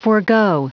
Prononciation du mot forego en anglais (fichier audio)
Prononciation du mot : forego